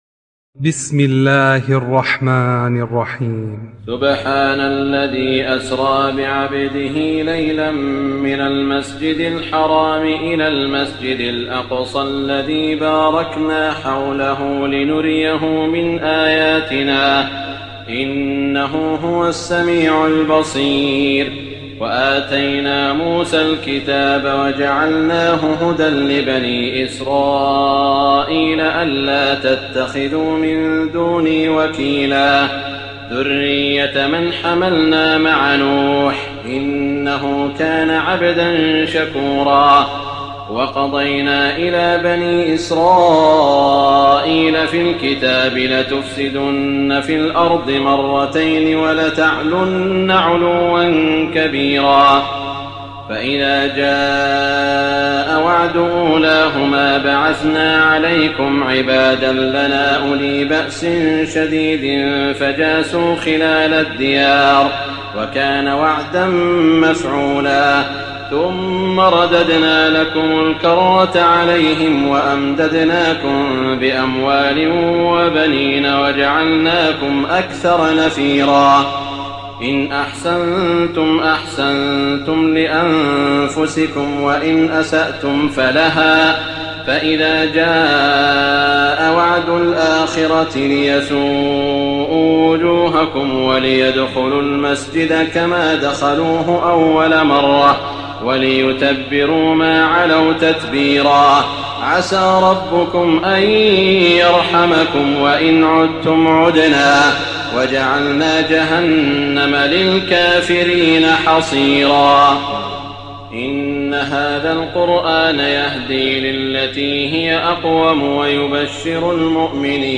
تراويح الليلة الرابعة عشر رمضان 1419هـ من سورة الإسراء (1-96) Taraweeh 14 st night Ramadan 1419H from Surah Al-Israa > تراويح الحرم المكي عام 1419 🕋 > التراويح - تلاوات الحرمين